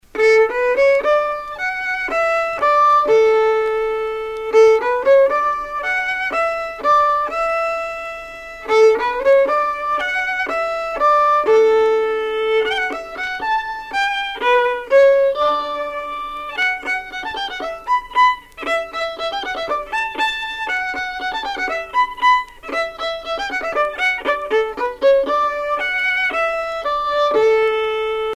Usage d'après l'analyste gestuel : danse
Pièce musicale éditée